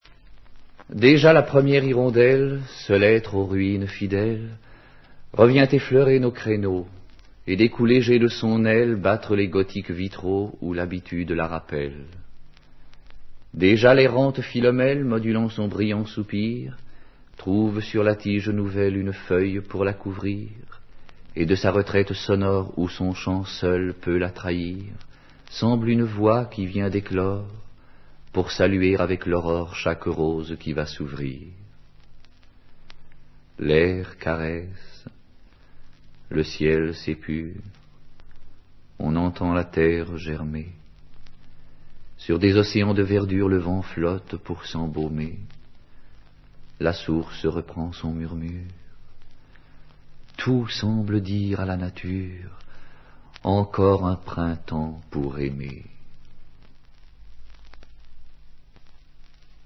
dit par Paul-Émile DEIBER